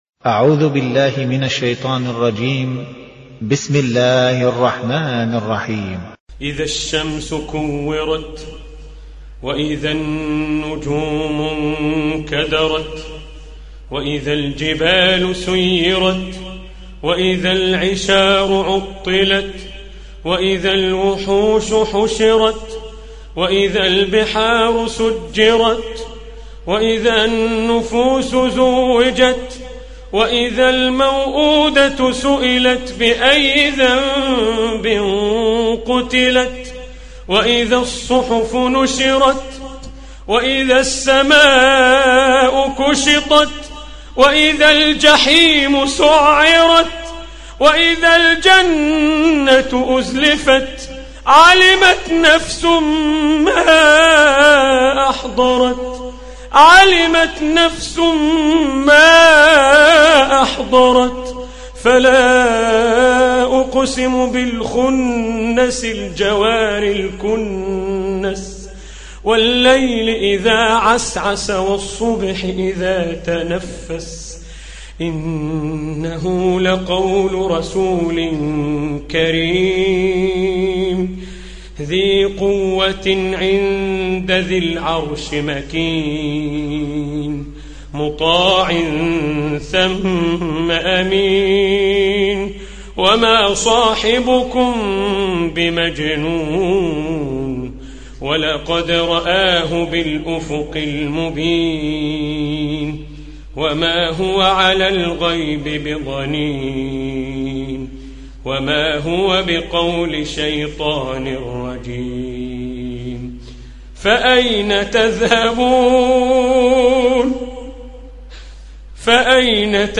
Surah Repeating تكرار السورة Download Surah حمّل السورة Reciting Murattalah Audio for 81. Surah At-Takw�r سورة التكوير N.B *Surah Includes Al-Basmalah Reciters Sequents تتابع التلاوات Reciters Repeats تكرار التلاوات